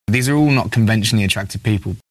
We can hear evidence of this if we listen to Sheeran himself being interviewed.
And this might conceivably be These a rule not conventionally attractive people:
but of course he’s saying These are all…